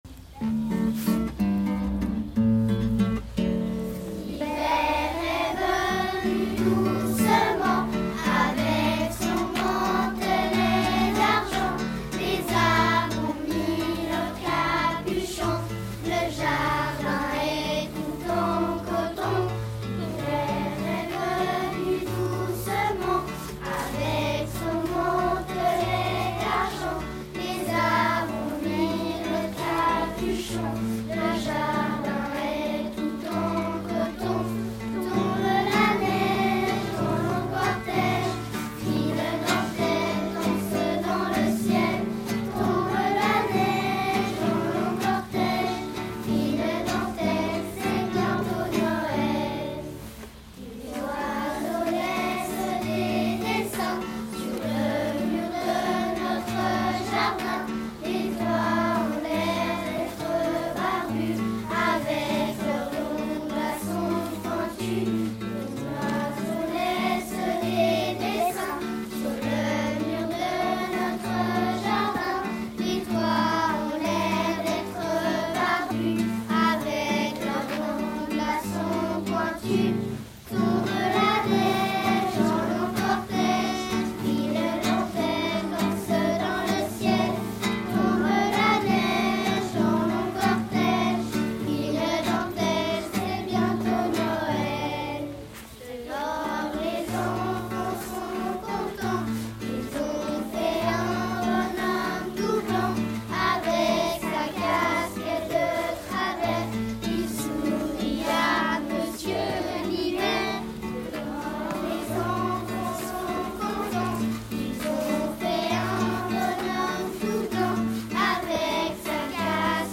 2021-22 : “Chantée de Noël”, les classes de Corcelles
Groupe 3 : classes 1-2P42, 5P41 et 3P42